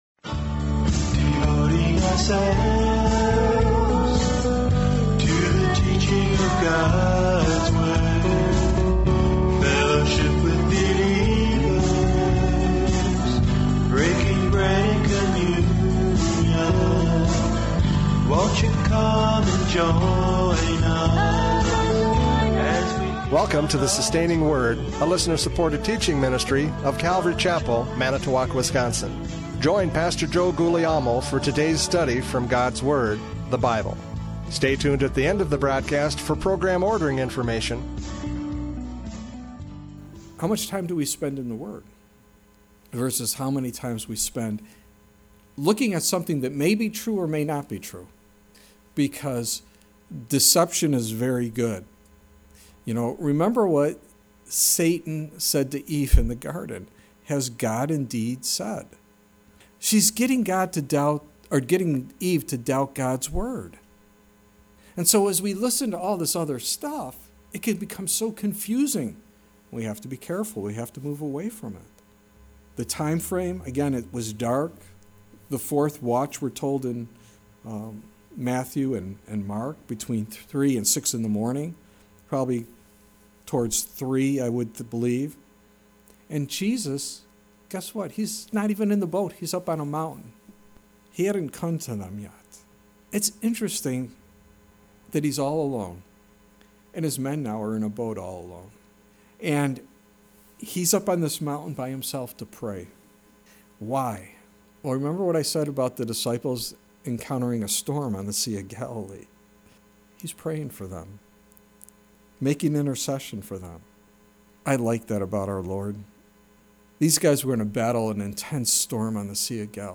John 6:15-21 Service Type: Radio Programs « John 6:15-21 The Eye of the Storm!